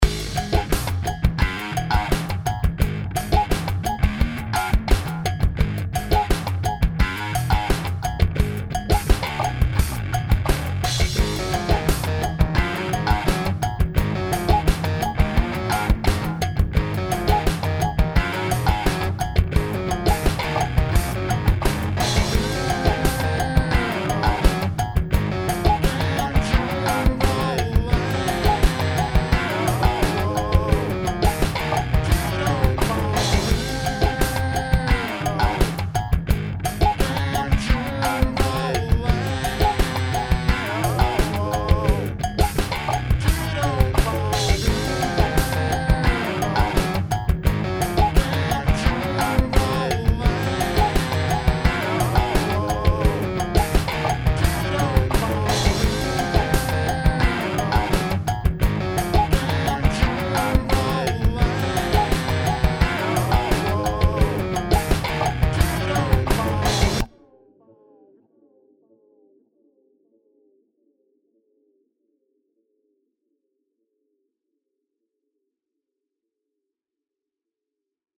home of the daily improvised booty and machines -
orginal 3 bass grooves